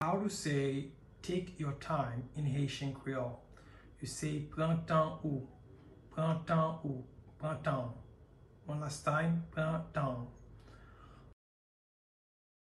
Listen to and watch “Pran tan ou” pronunciation in Haitian Creole by a native Haitian  in the video below:
Take-your-time-in-Haitian-Creole-Pran-tan-ou-pronunciation-by-a-Haitian-teacher.mp3